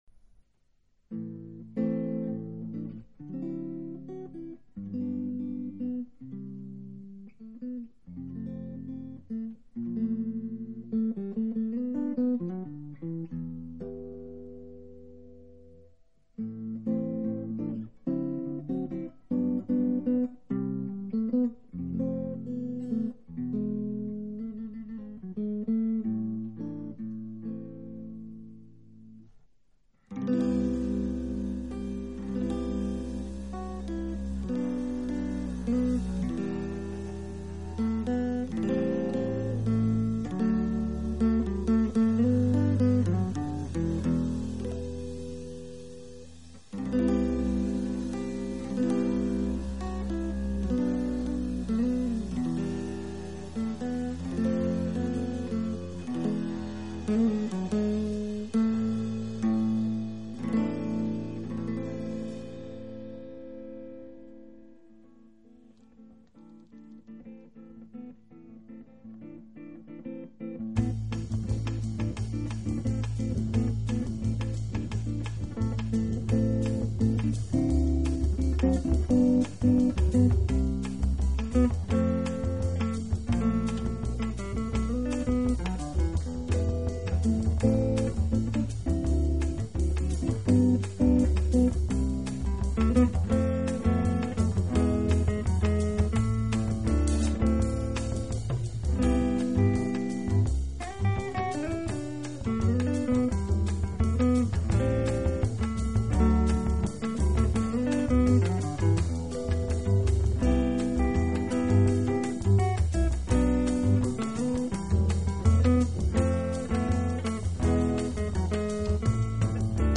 这是爵士风格的版本。